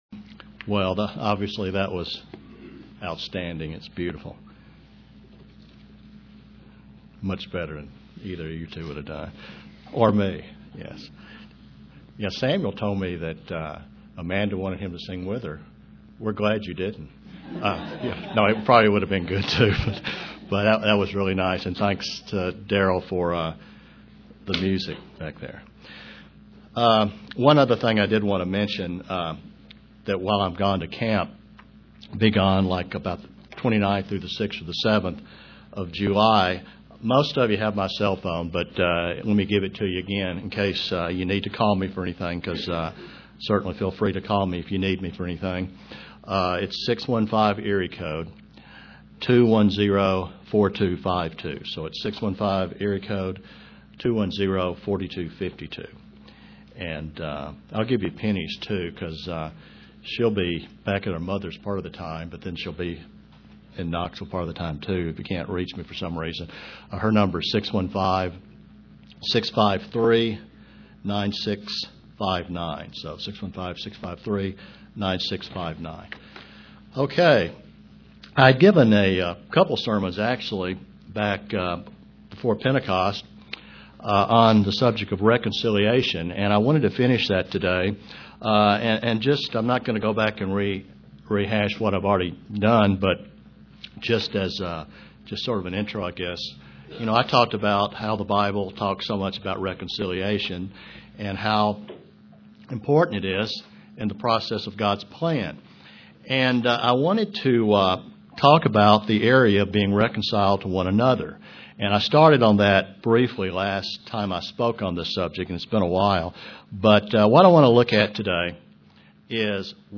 Print How to respond when we offend others UCG Sermon Studying the bible?